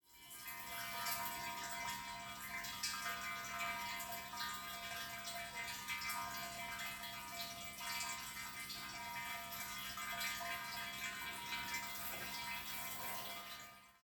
水琴窟
（音）　※ 音は早送りではありません。水量が多いためです。